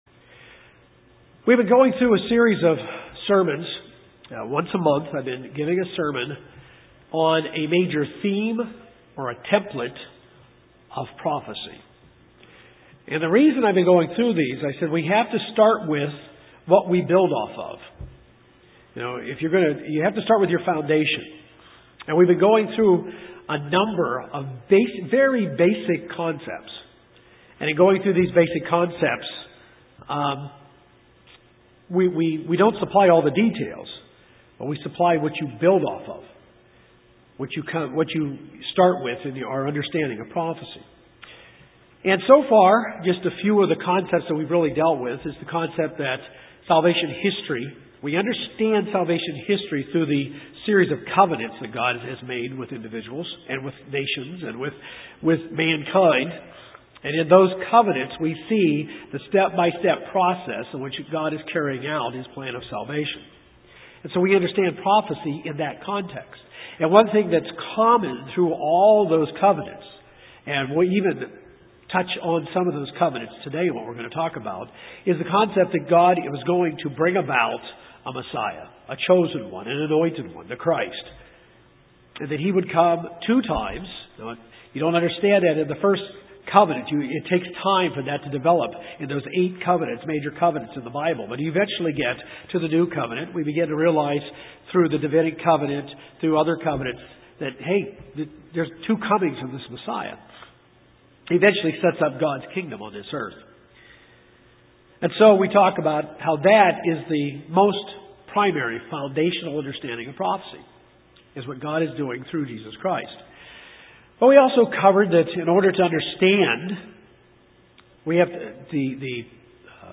This sermon is part four in a series on understanding prophecy in the Bible.